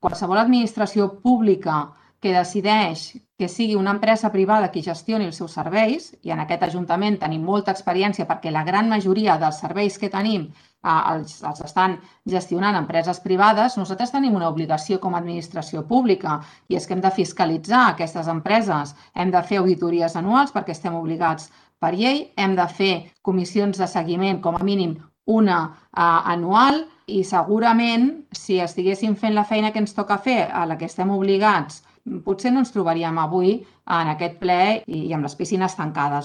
Ple extraordinari Bosc Tancat